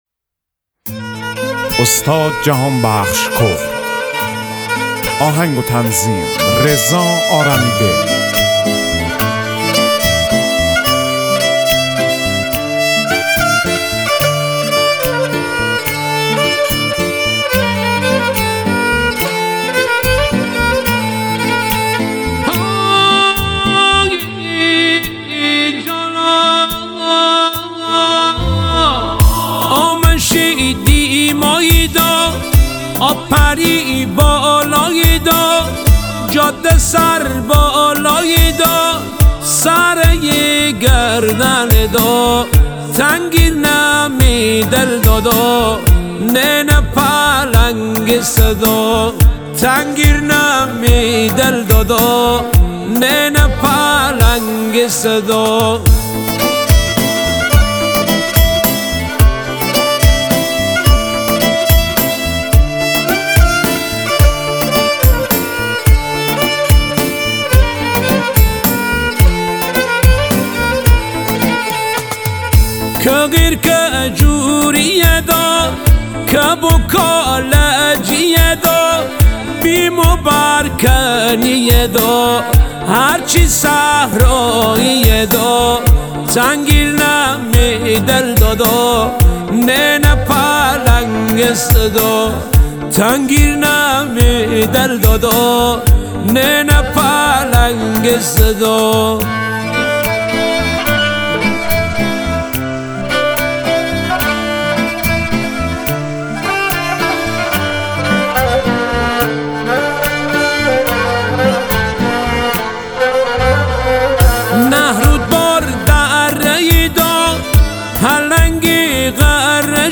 موزیک مازندرانی